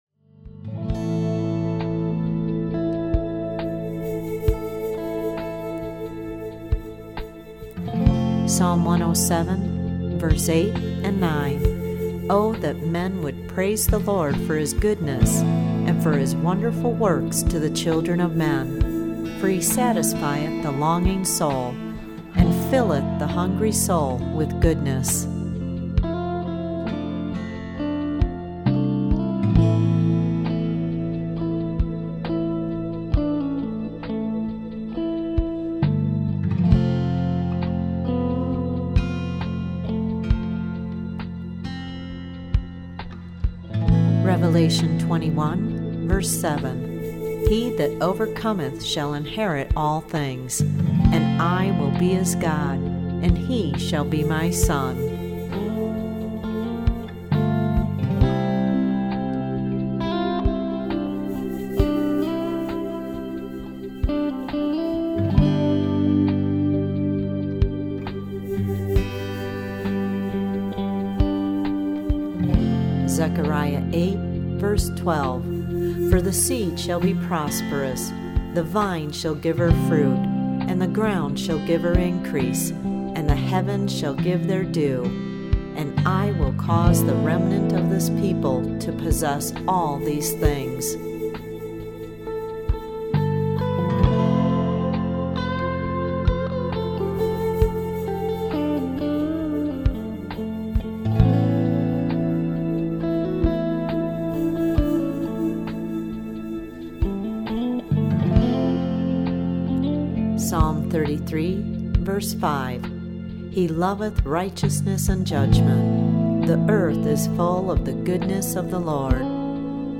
scripture narration
anointed instrumental music to give you time to think about each passage before you hear the next one -- thus the name Scripture Meditations.